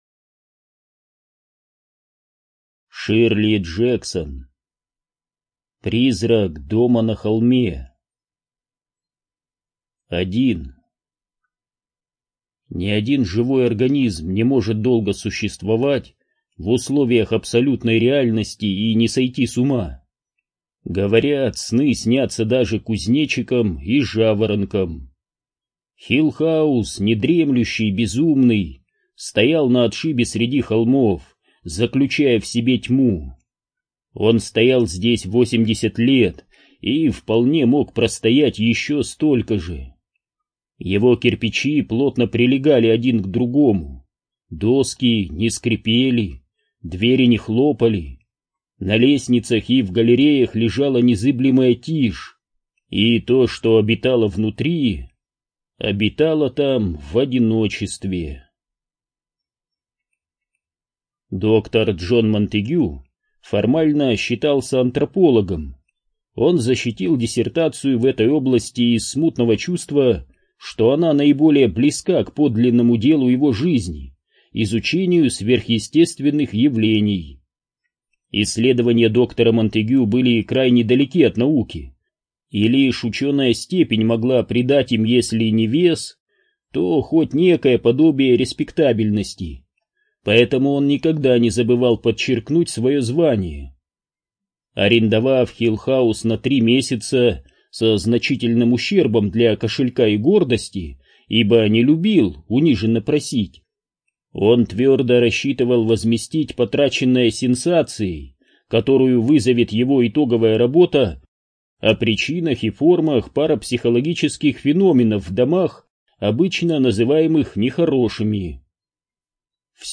ЖанрУжасы и мистика
скажу свой коментарий читец начитал эту книгу с чупачупцом ворту или с какой нибудь сосательной конфетой.